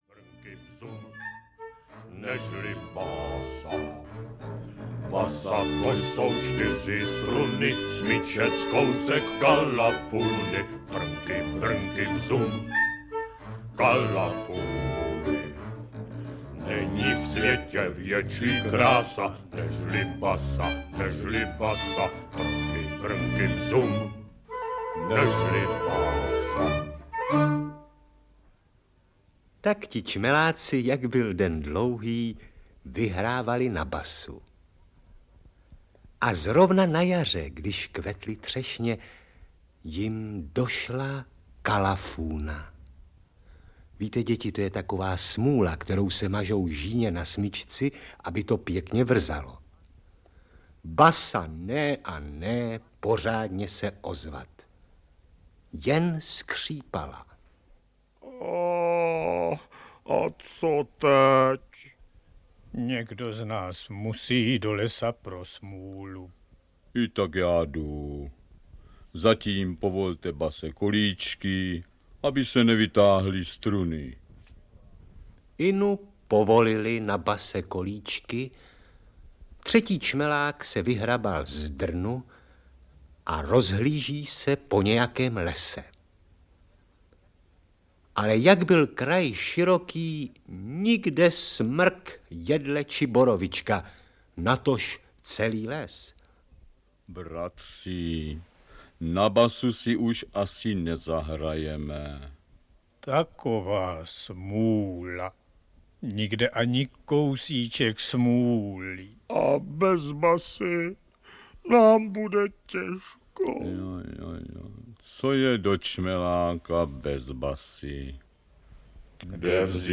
Audio knihaAlbum pohádek
Ukázka z knihy